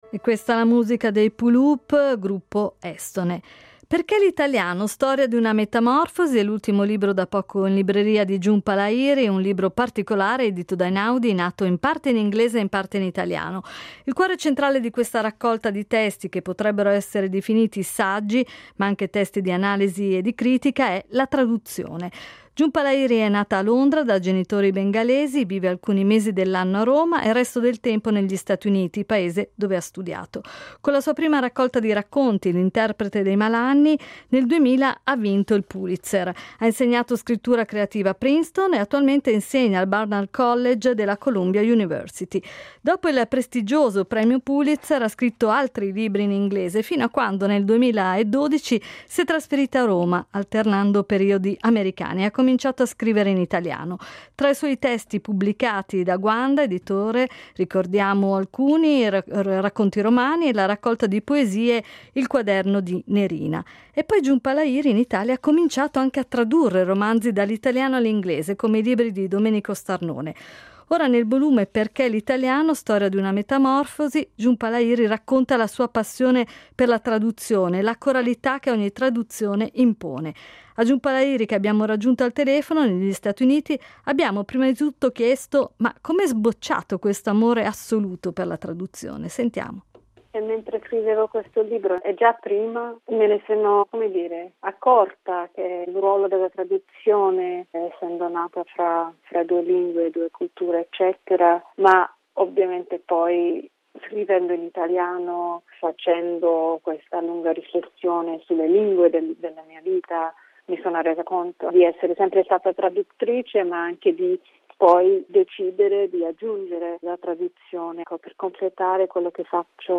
A Jhumpa Lahiri che abbiamo raggiunto al telefono negli Stat Uniti abbiamo prima di tutto chiesto come è sbocciato questo amore assoluto per la traduzione.